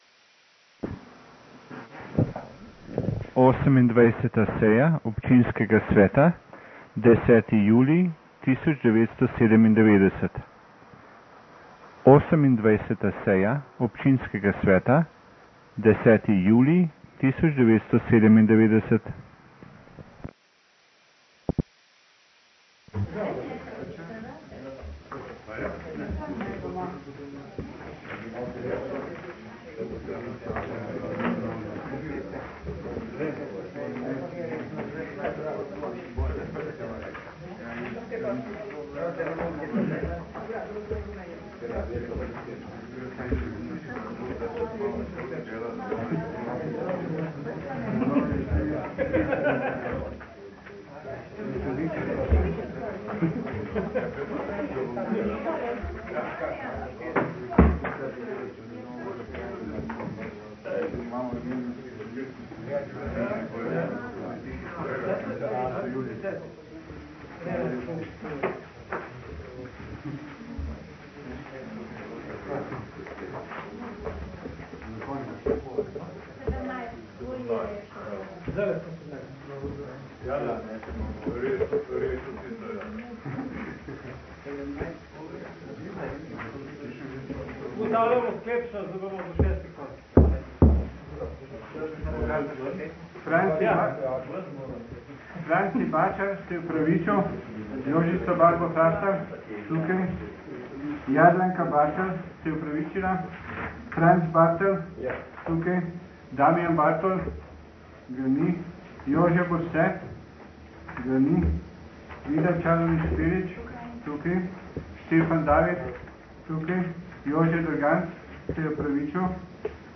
28. seja Občinskega sveta Mestne občine Novo mesto - Seje - Občinski svet - Mestna občina